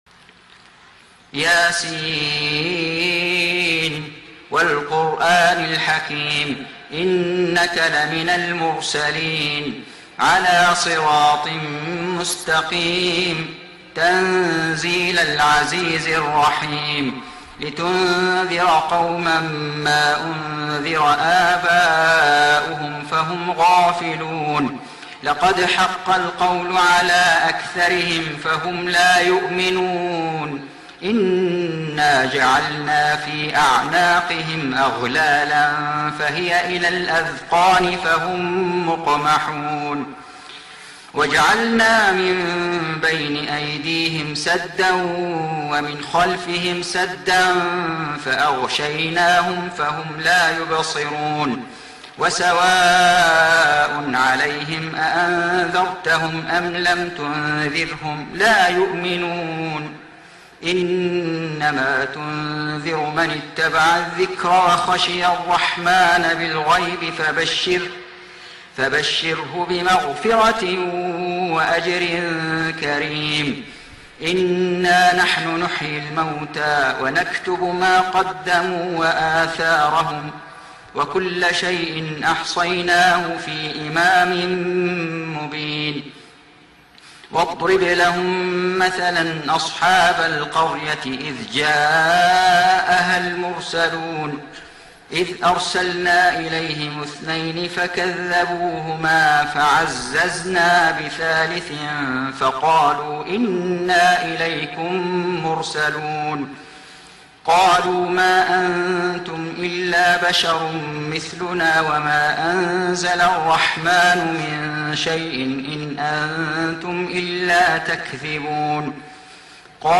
سورة يس > السور المكتملة للشيخ فيصل غزاوي من الحرم المكي 🕋 > السور المكتملة 🕋 > المزيد - تلاوات الحرمين